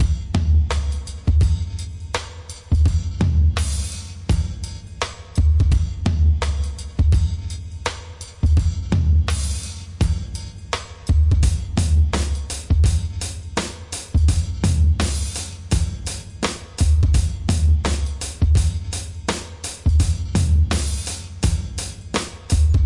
忙碌的混响循环90bpm " 90bpm 2bar忙碌的混响暂停
描述：粗糙的TripHop循环，带有一些令人作呕的混响效果。在FLStudio中编程，
标签： 节拍 突破 故障 出问题 混响 节奏 口吃 行程跳
声道立体声